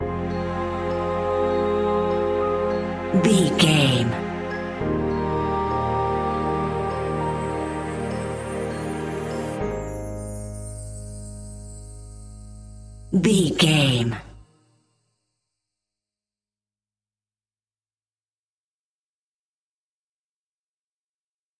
A great piece of royalty free music
Aeolian/Minor
repetitive
calm
electronic
synths